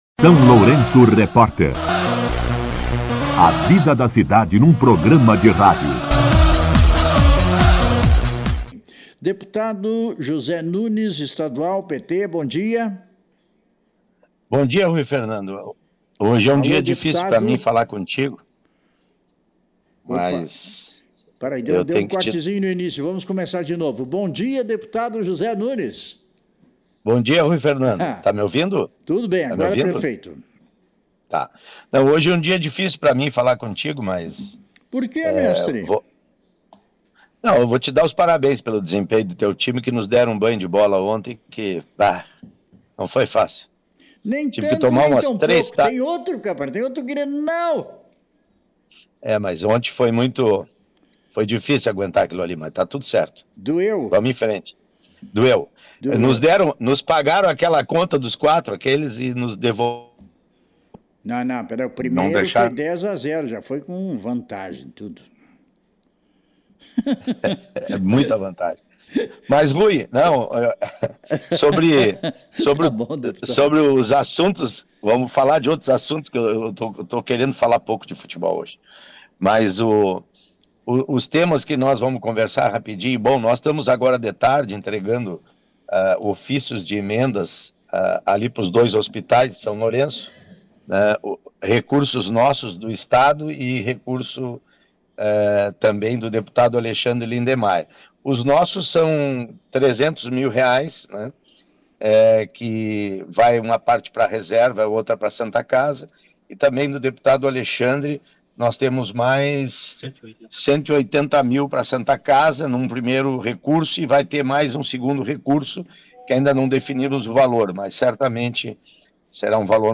Entrevista com o deputado estadual Zé Nunes
O deputado estadual Zé Nunes concedeu entrevista ao SLR RÁDIO na manhã desta segunda-feira (2) para falar sobre o futuro da BR-116, especialmente no trecho que impacta diretamente a região Sul do Estado.